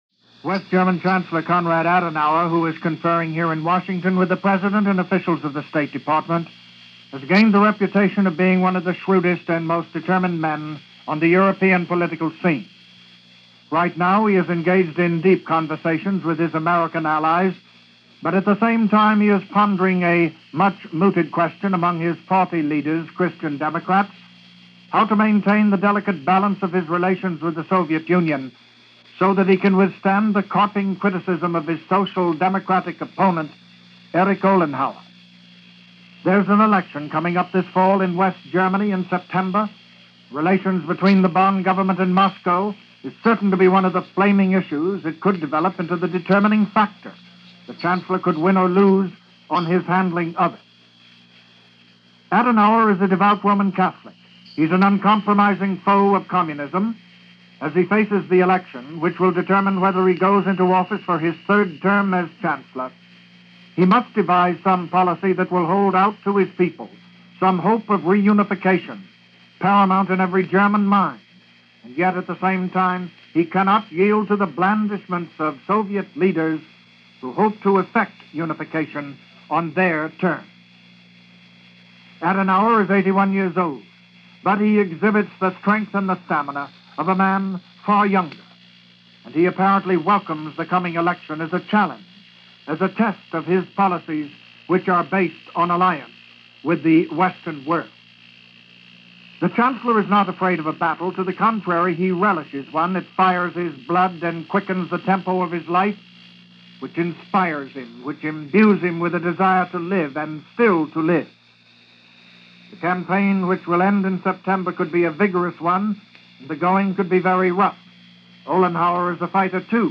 And giving a commentary on Konrad Adenauer and the days concerns was noted News Analyst